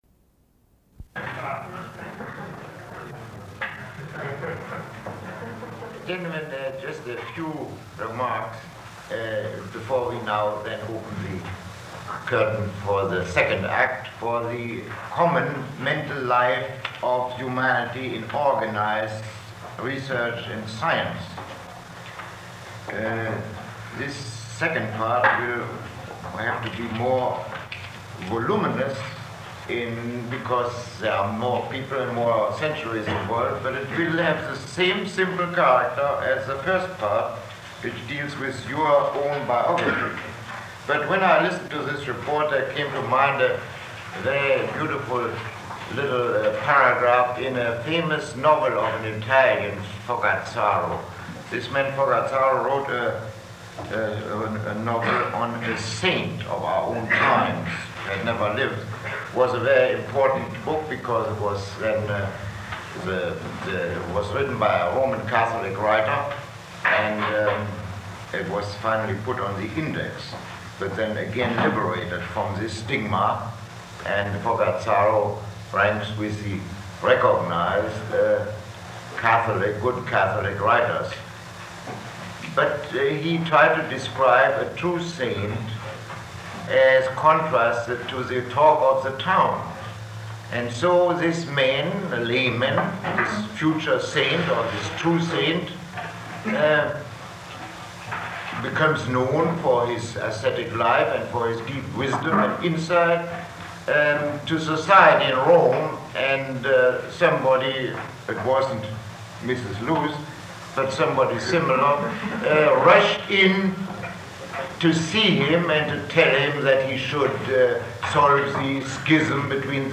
Lecture 10